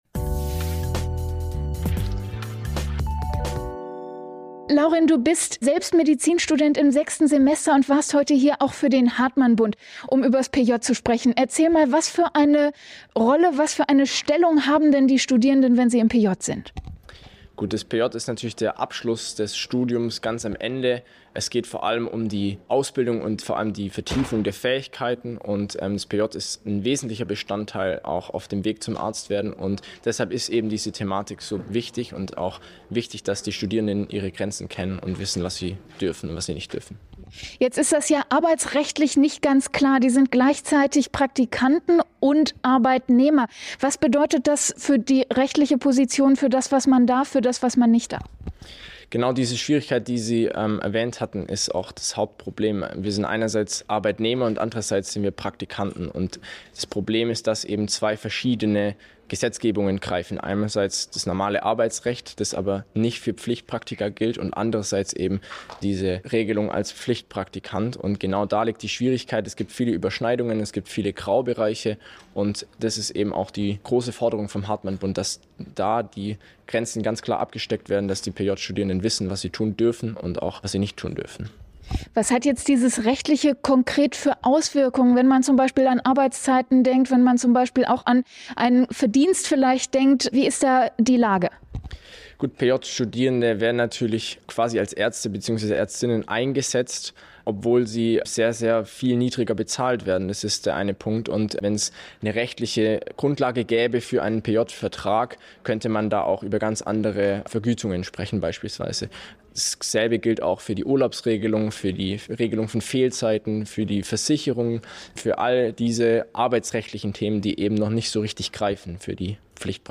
Interview
Im Interview am Rande des Operation Karriere-Kongresses in Essen am